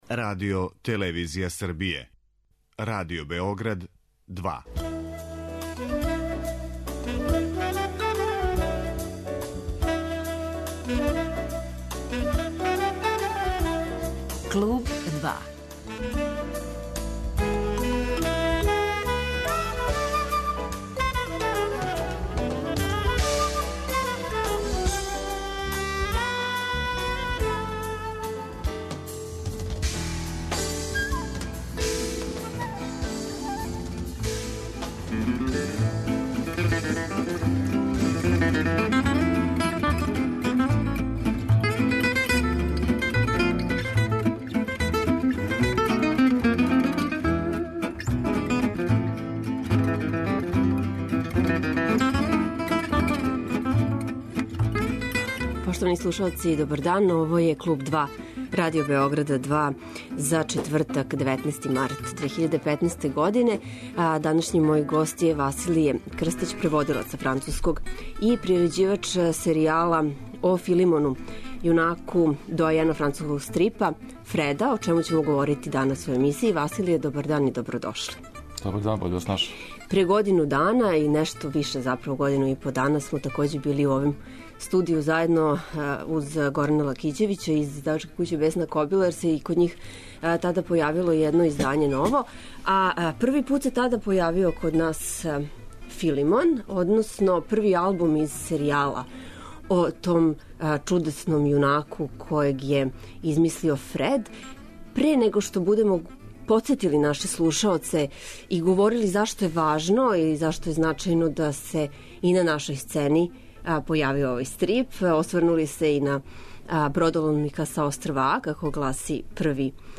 Са нашим данашњим гостом разговарамо о новој свесци "Филомон: Дивљи клавир" (Sirius production), о поетици стрипа, превођењу са француског...